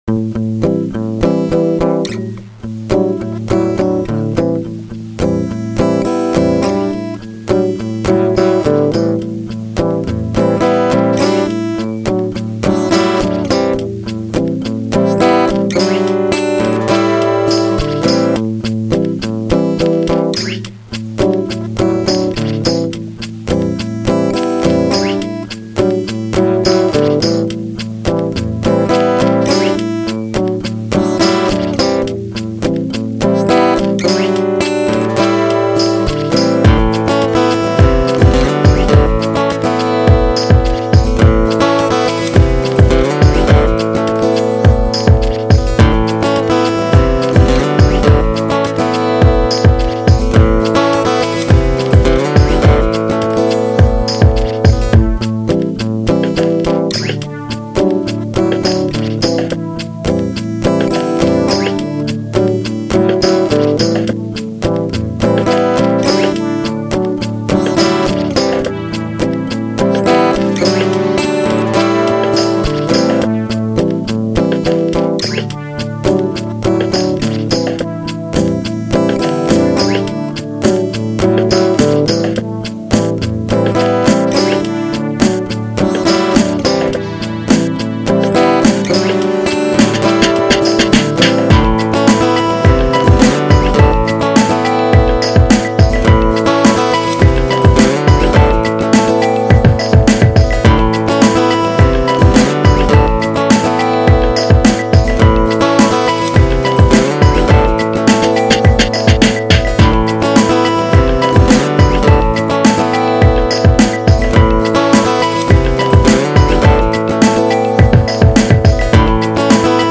105bpm
guitar - has a really nice lilt to it.